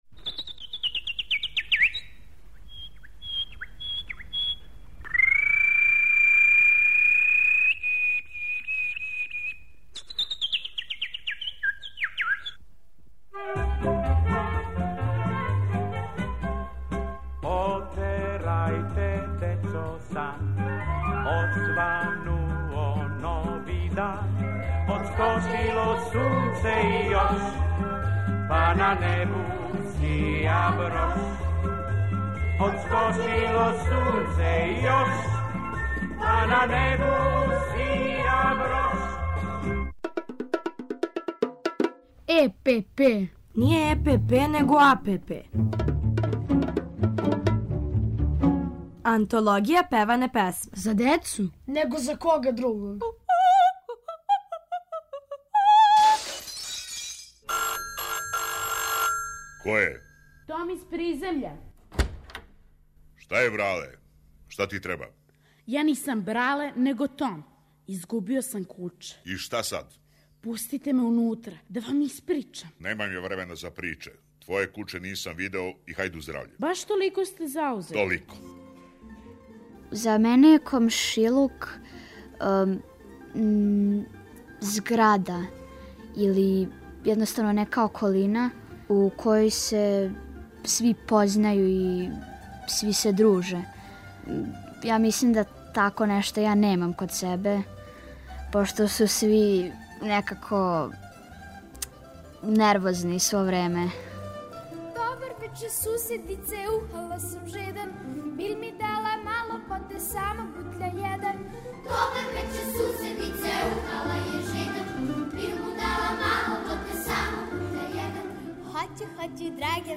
У серијалу АНТОЛОГИЈА ПЕВАНЕ ПЕСМЕ певамо и размишљамо о комшилуку, уз Колибри и Дечју драмску групу Радио Београда.